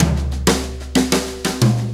Brushes Fill 69-10.wav